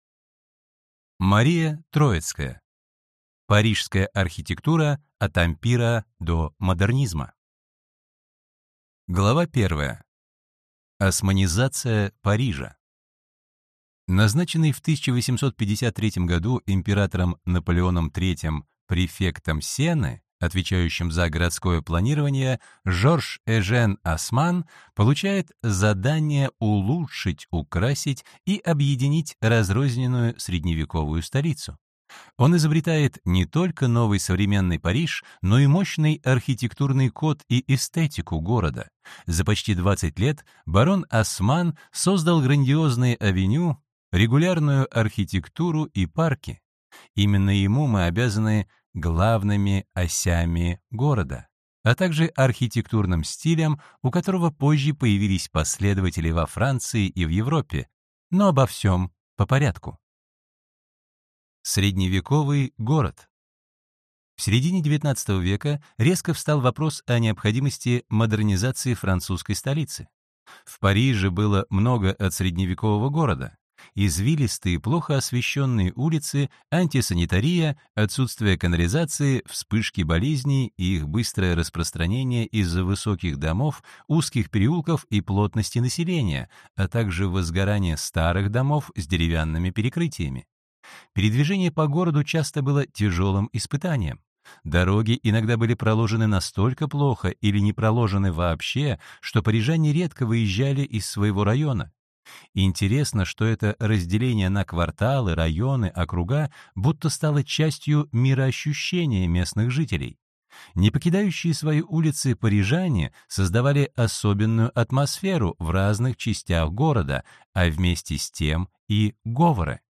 Аудиокнига Парижская архитектура: от ампира до модернизма | Библиотека аудиокниг